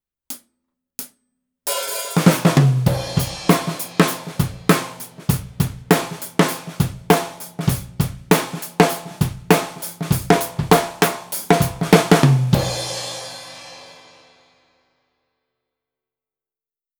AKG C451B一本のみでドラムを録ってみました。
非常にバランスが良いですね！
スネアも胴鳴り感が出て良いサウンドですね！